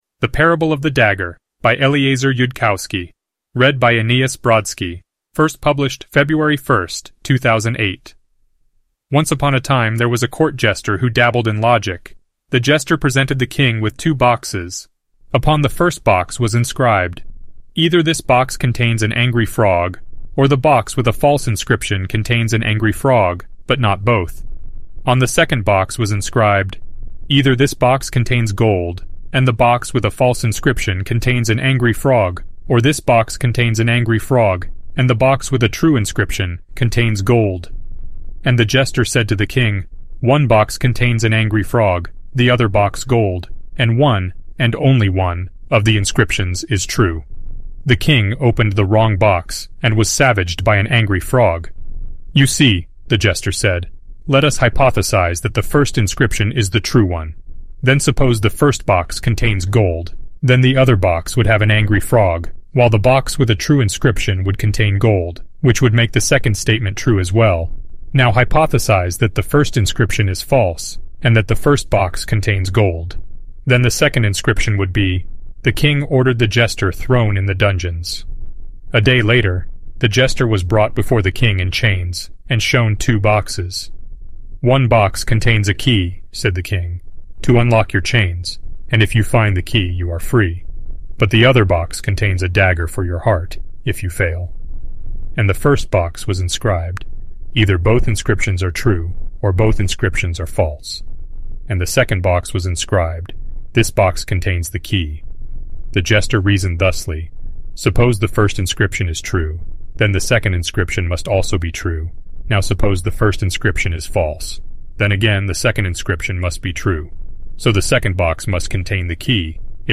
the other one is a voice-matching AI.
synthesized_audio.mp3